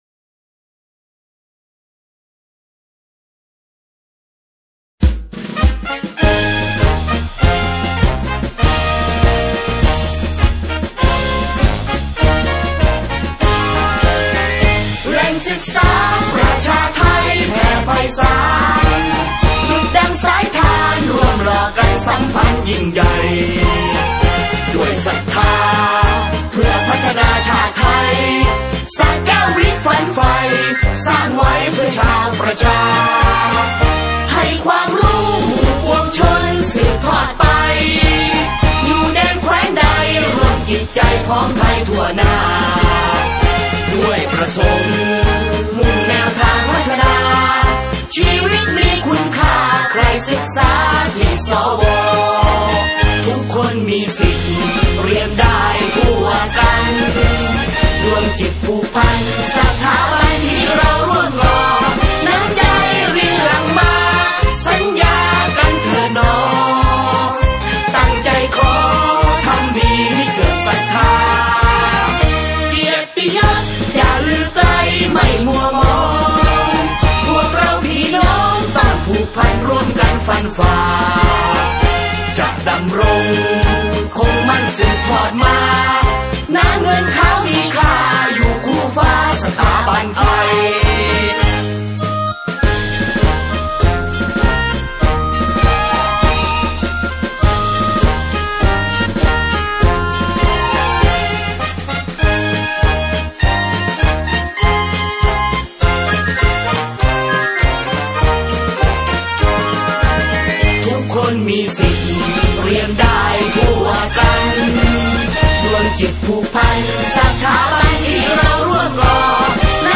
เพลงมาร์ชโรงเรียน